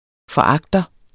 Udtale [ fʌˈɑgdʌ ]